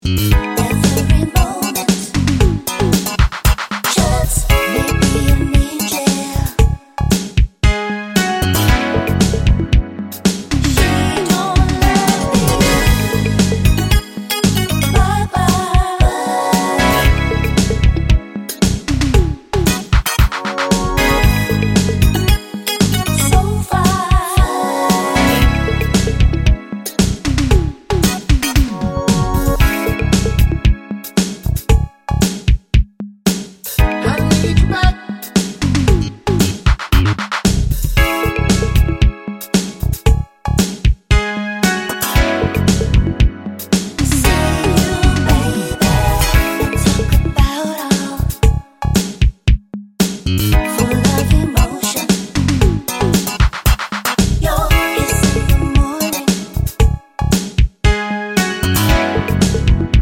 no Backing Vocals Soul / Motown 4:32 Buy £1.50